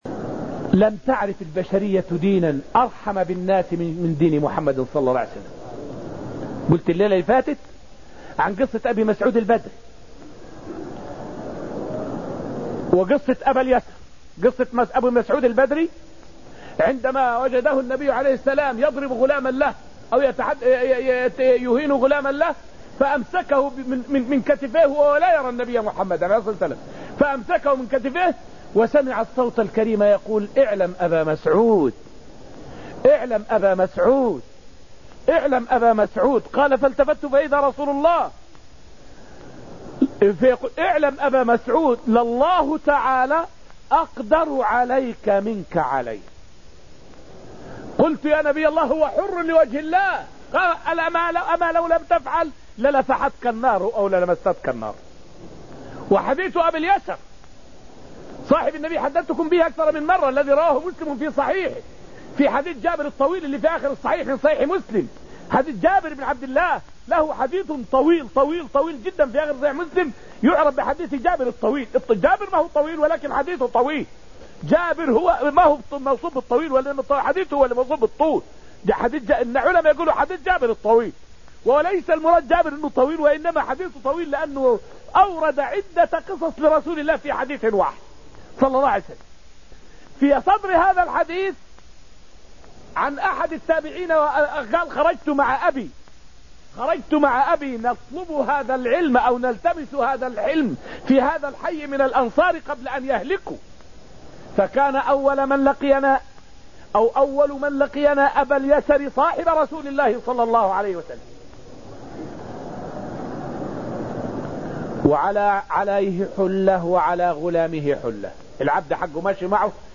فائدة من الدرس الثالث من دروس تفسير سورة المجادلة والتي ألقيت في المسجد النبوي الشريف حول الإسلام دين الرحمة والإنسانية.